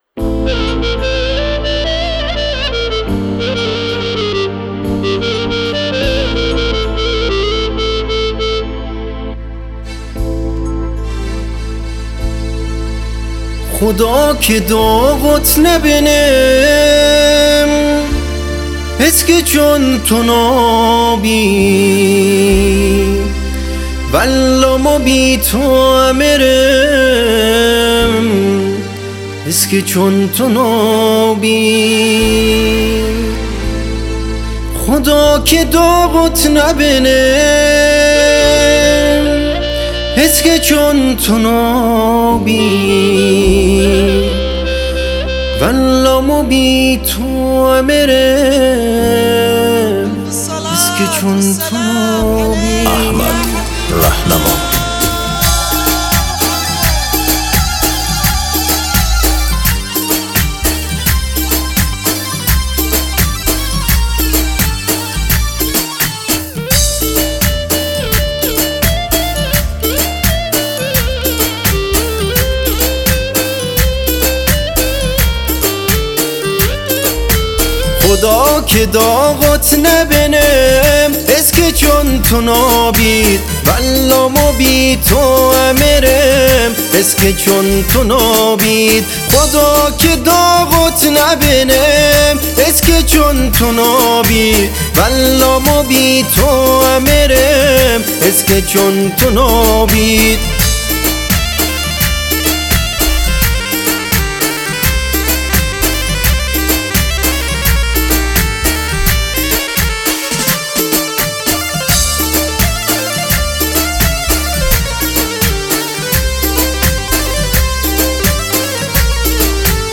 کیبورد